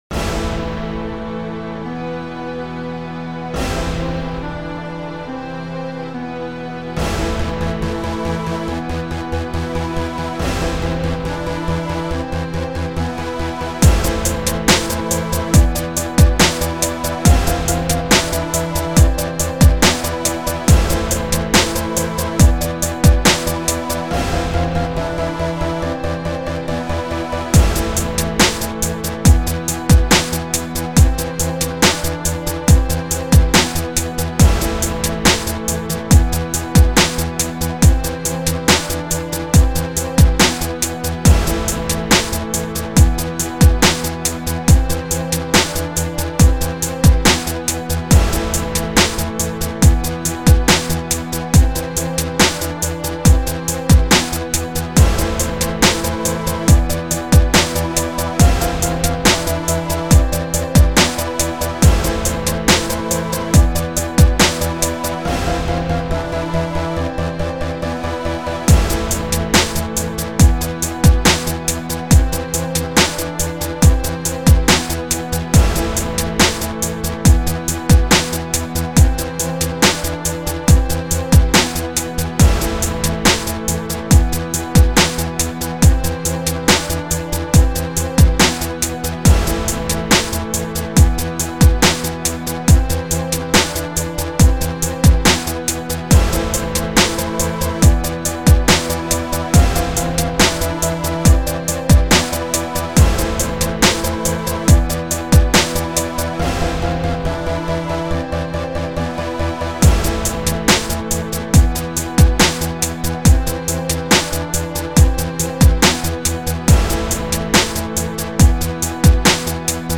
HipHop Tracks & Instrumentals
Inst.